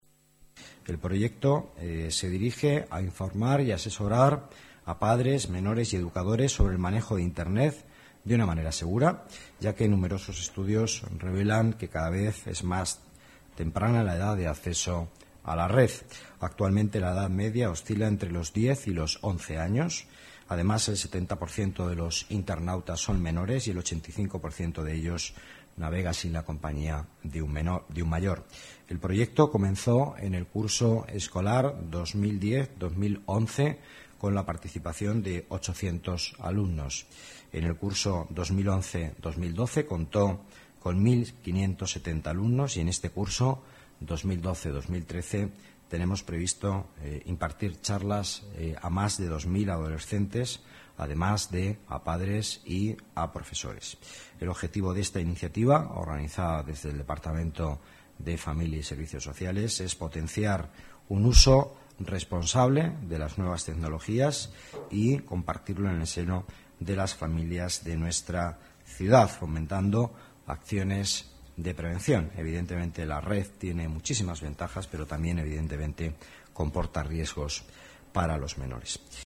Nueva ventana:Declaraciones del vicealcalde de Madrid, Miguel Ángel Villanueva